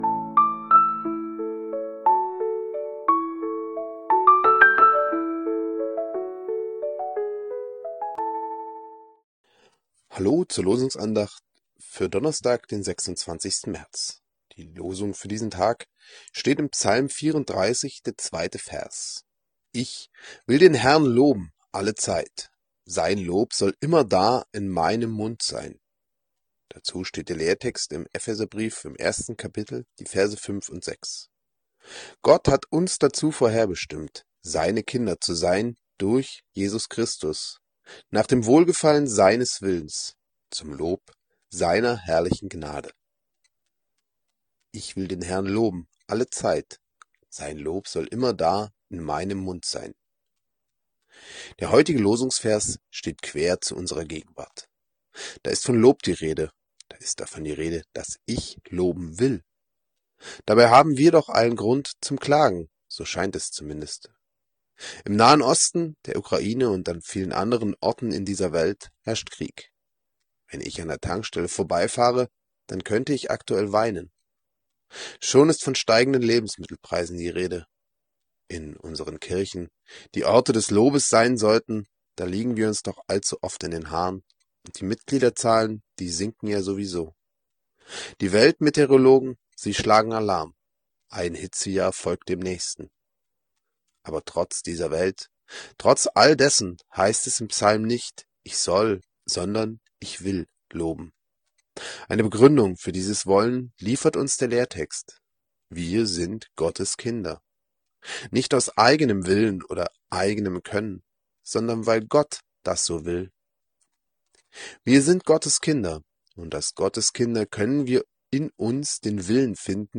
Losungsandacht für Donnerstag, 26.03.2026